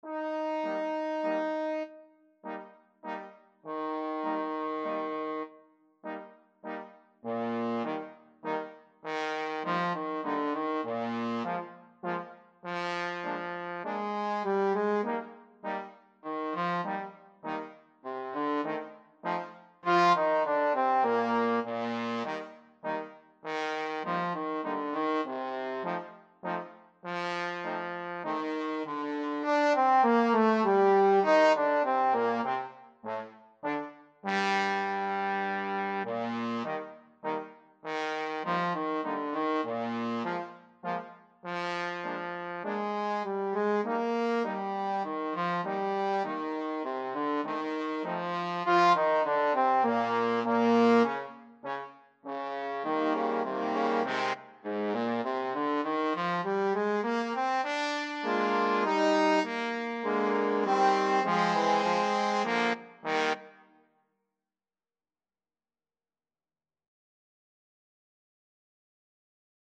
Free Sheet music for Trombone Quartet
Trombone 1Trombone 2Trombone 3Trombone 4
Eb major (Sounding Pitch) (View more Eb major Music for Trombone Quartet )
3/4 (View more 3/4 Music)
Slowly = c.100
Easy Level: Recommended for Beginners with some playing experience